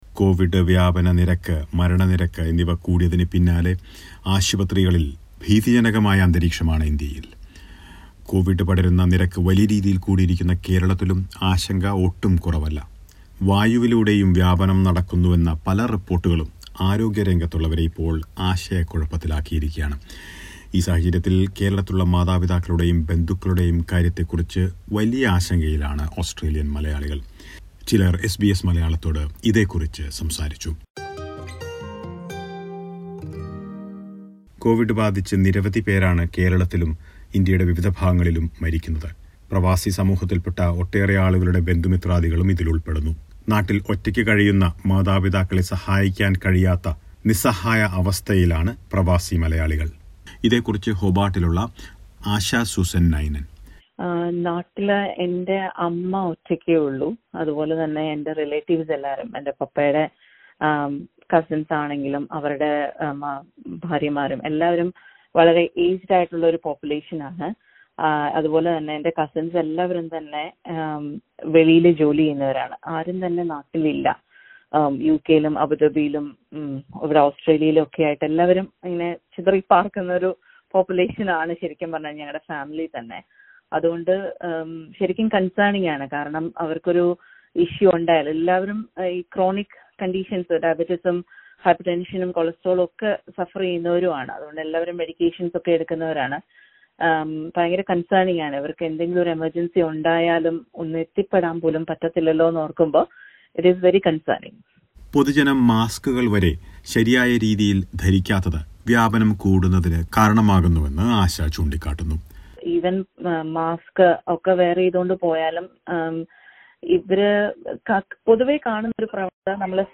Covid crisis in India has forced Australian authorities to close its borders to India temporarily, leaving many migrants in dilemma. Some Malayalees in Australia speak about their helpless situation.